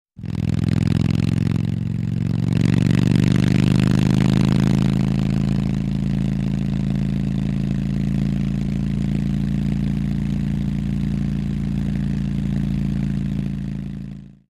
Harley-Davidson|OB
Harley Motorcycle On Board At Various Speeds, With Gear Shifts